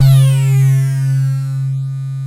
ARP BAS C2-L.wav